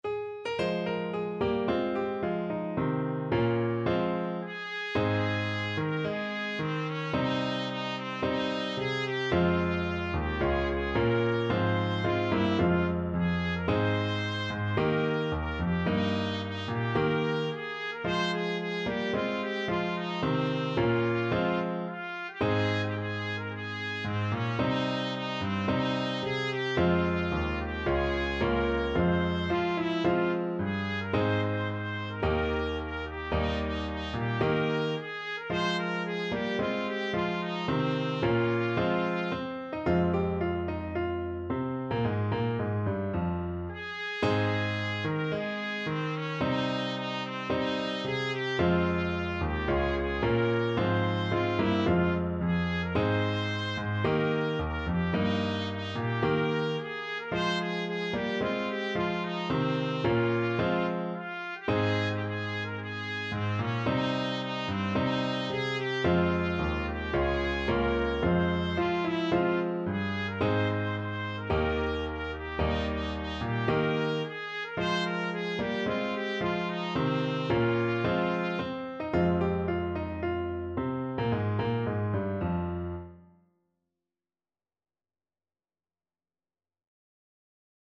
4/4 (View more 4/4 Music)
~ = 110 Allegro (View more music marked Allegro)
Traditional (View more Traditional Trumpet Music)